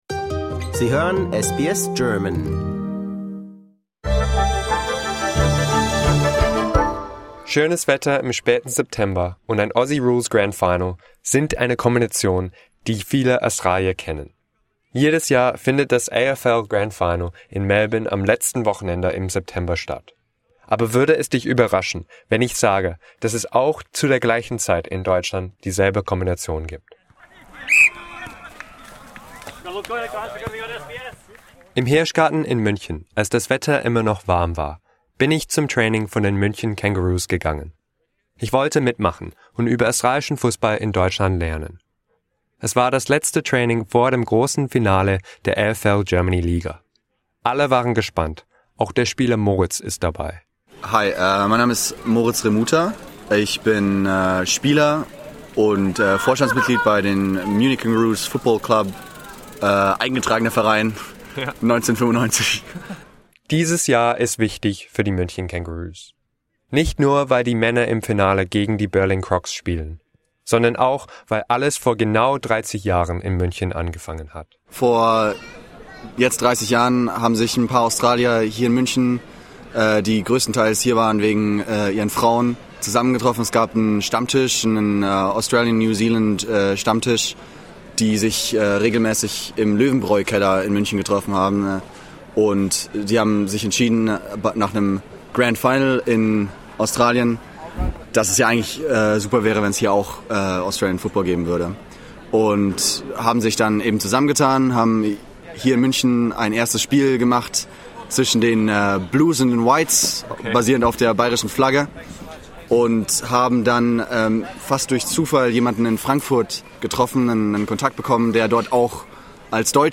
SBS Deutsch